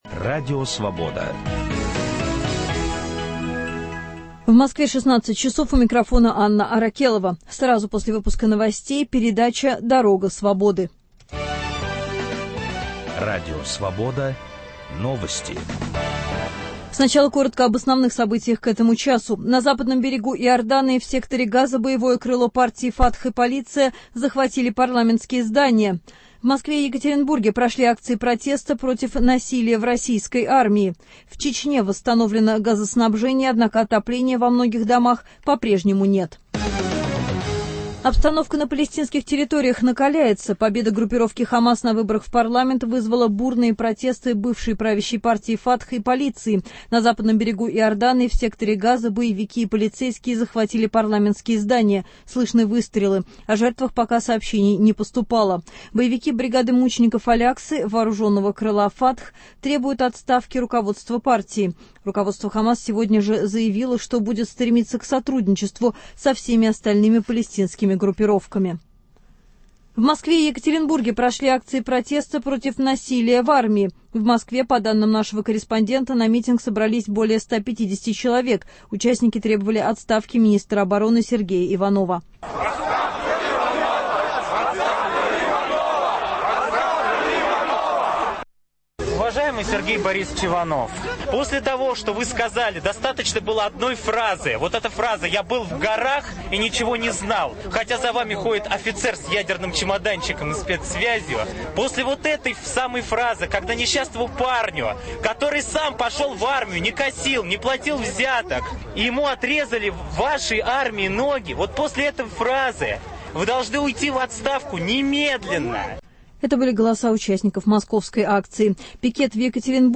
Нападки на правозащитников, или кому выгоден шпионский скандал? В круглом столе участвуют